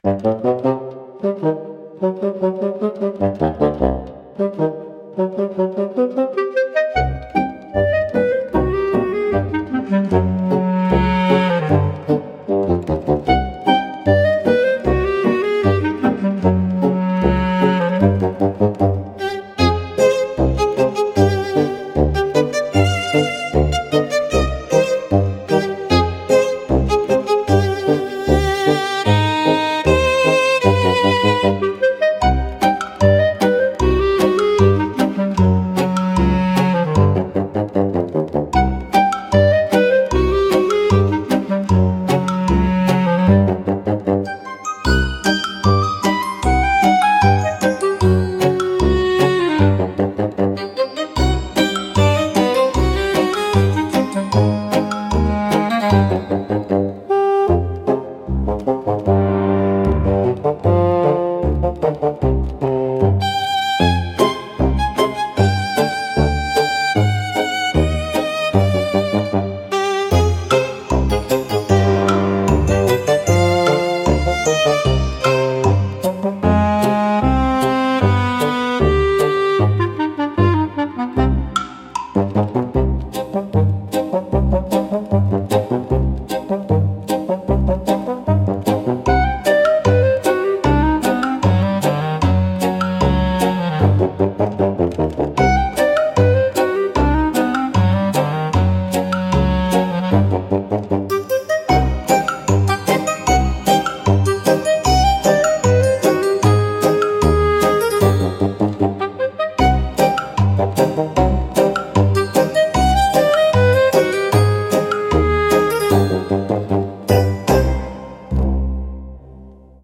おとぼけは、バスーンとシロフォンを主体としたコミカルでドタバタした音楽ジャンルです。
視聴者の笑いを誘い、軽快で親しみやすいムードを演出しながら、退屈を吹き飛ばします。